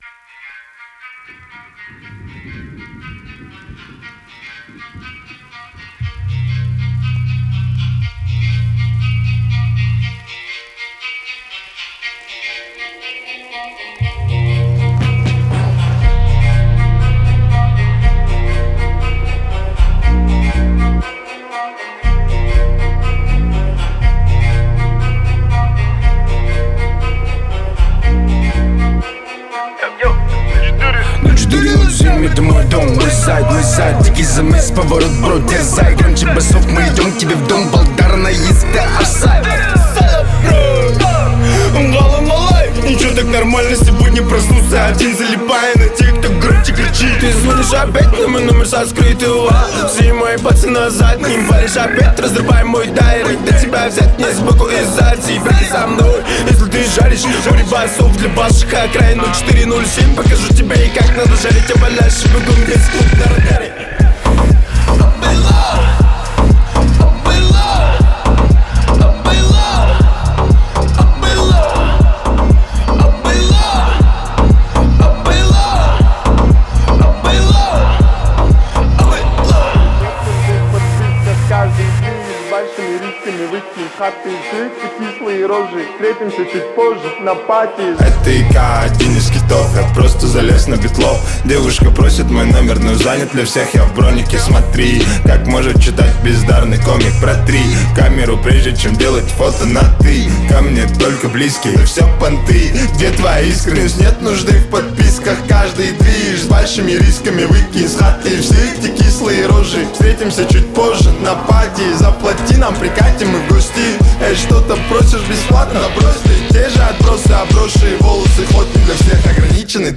элементы поп и хип-хопа